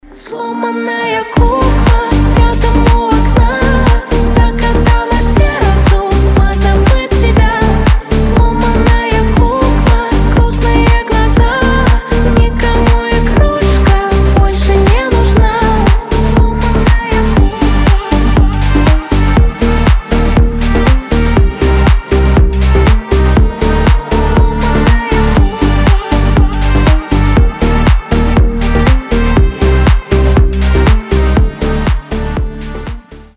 • Качество: 320, Stereo
грустные
Dance Pop
красивый женский голос